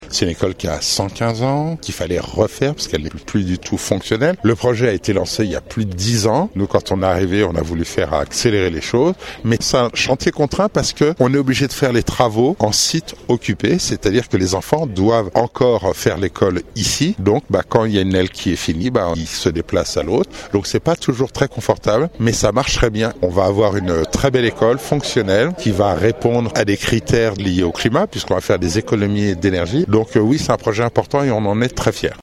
Sa rénovation aujourd’hui est donc nécessaire pour améliorer le confort des écoliers, mais aussi la performance énergétique du bâtiment. François Astorg, le maire d’Annecy nous en parle